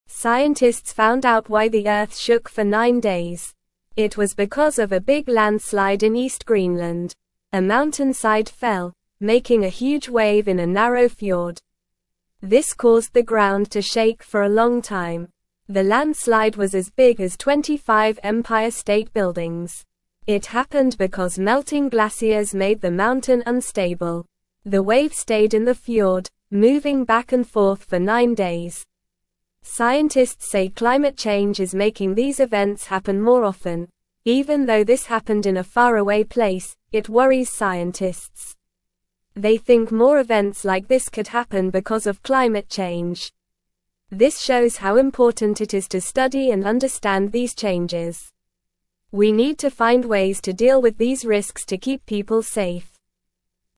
Normal
English-Newsroom-Lower-Intermediate-NORMAL-Reading-Big-Wave-Shook-Earth-for-Nine-Days-Straight.mp3